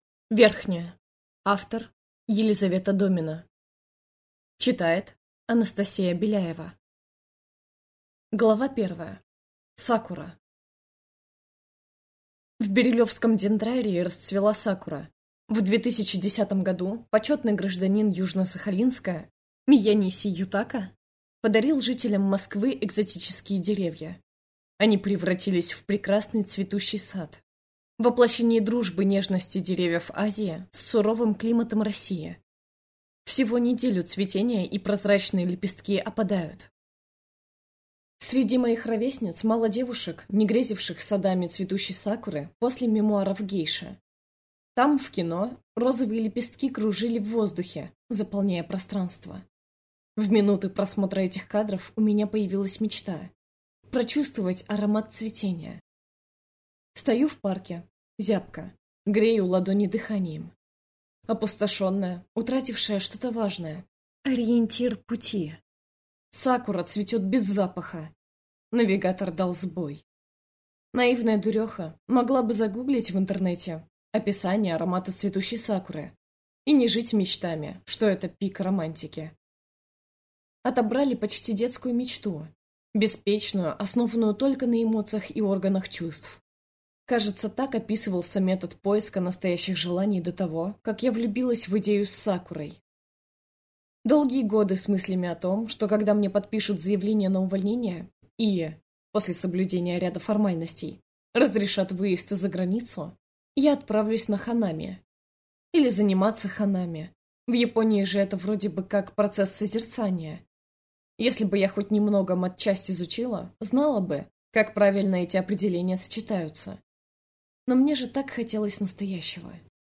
Аудиокнига Верхняя | Библиотека аудиокниг